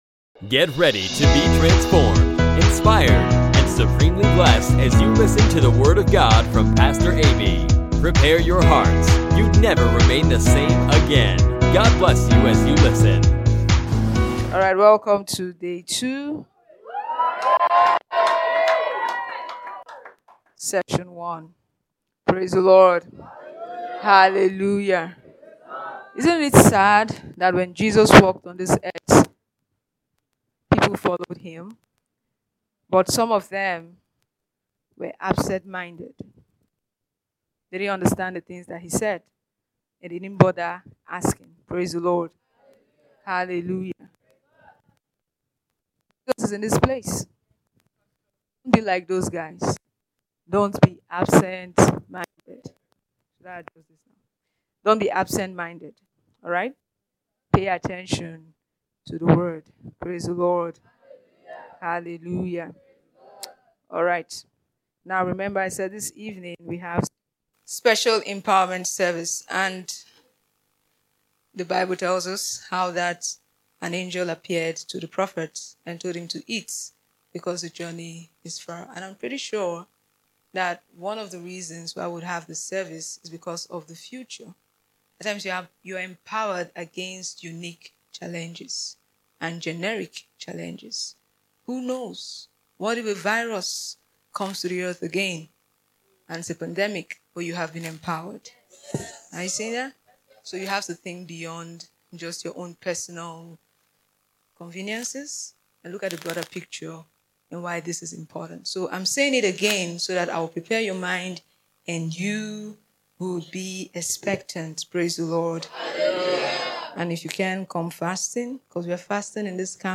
mmcm-2025-day-2-morning.mp3